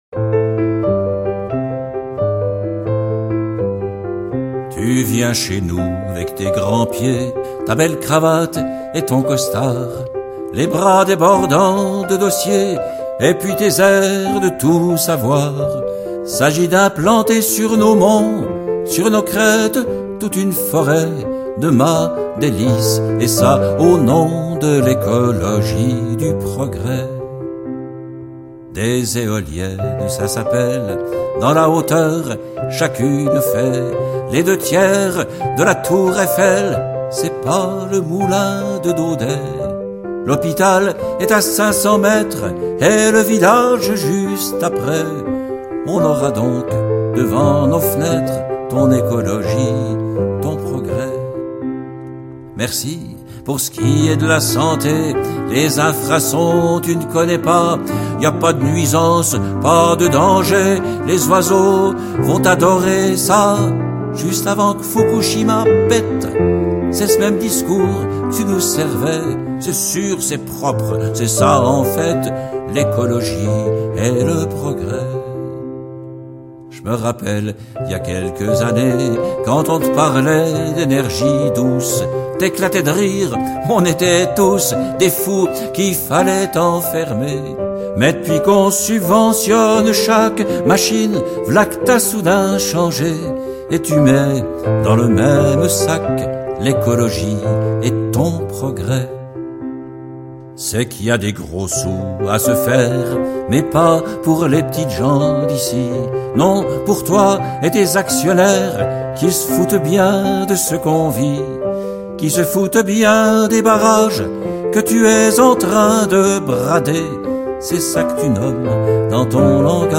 claviers, instrument midi
Guitares
Accordéon, bandonéon
Contrebasse, basse électrique
Batterie, percussions